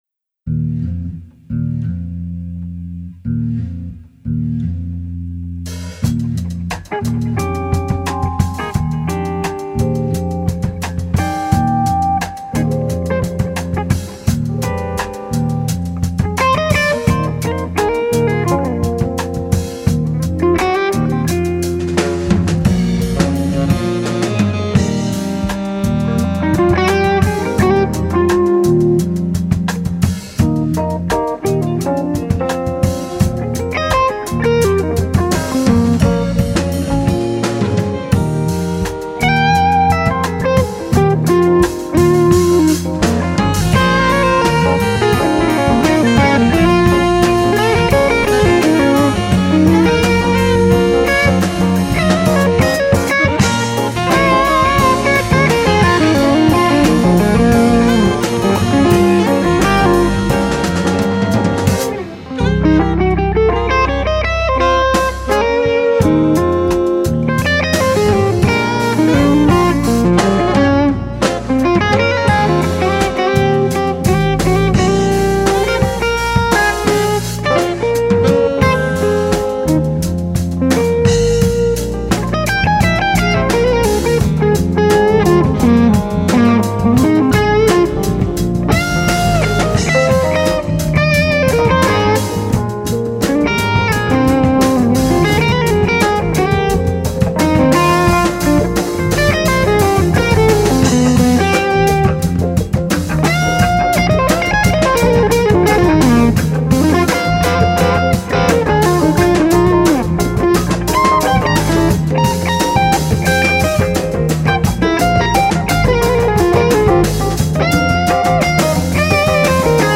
All Red White and Blue speakers and Baker RF on bridge hum. SM57 mic.
Non HRM 80s voice, tradional 220k network, Tonespotter speaker